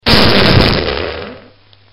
Fart Bomb Sound Effect Free Download
Fart Bomb